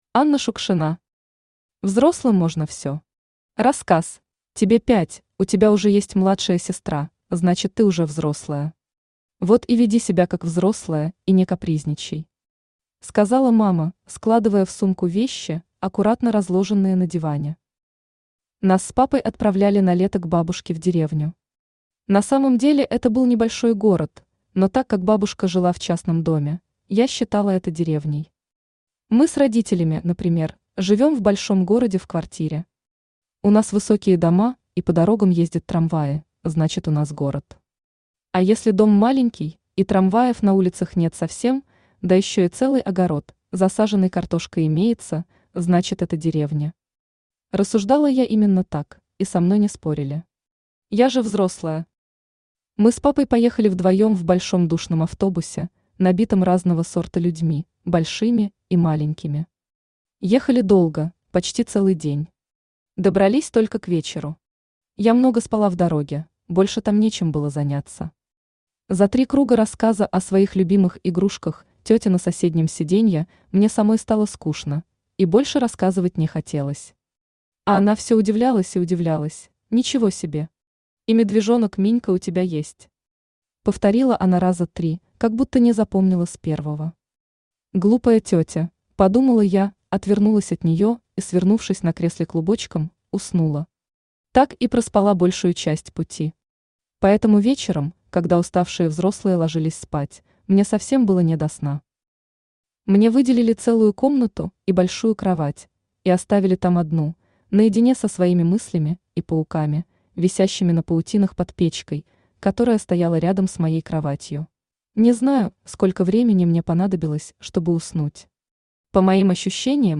Аудиокнига Взрослым можно все. Рассказ | Библиотека аудиокниг
Рассказ Автор Анна Шукшина Читает аудиокнигу Авточтец ЛитРес.